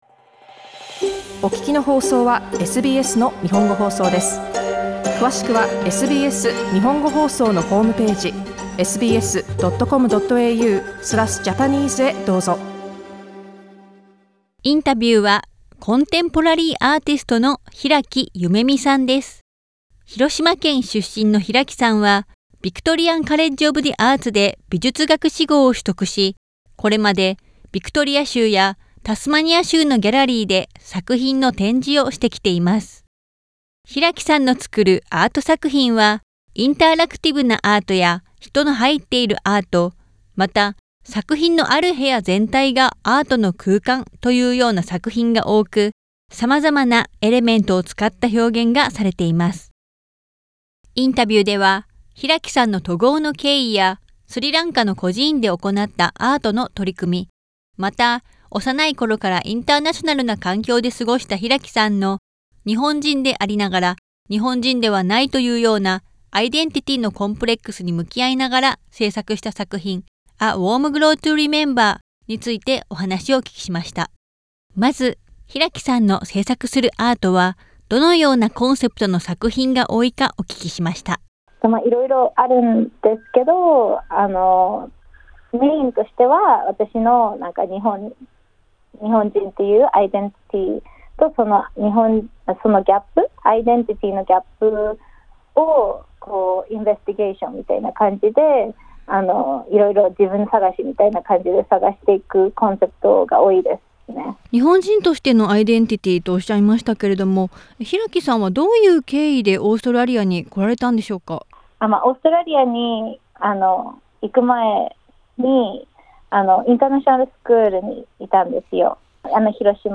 SBSの日本語放送